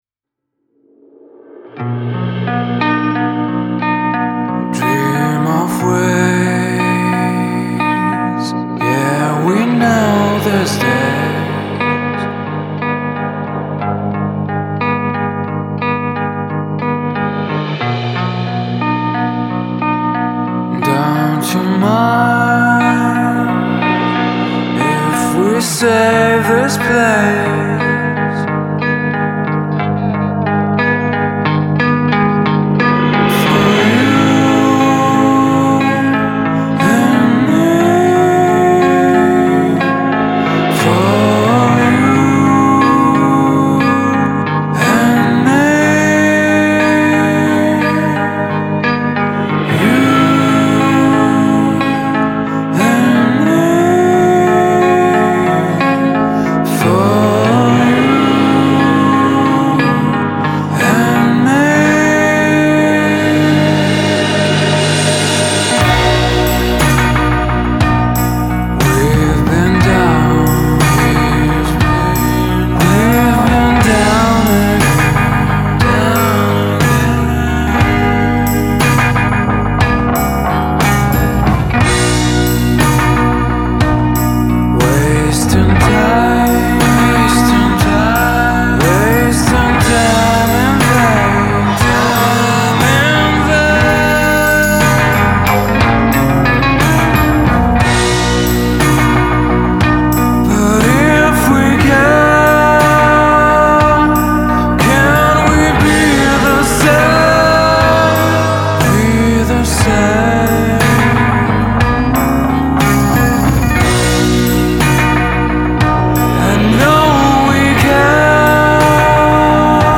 voice has a depth and elasticity
a swaying ballad with heft and purpose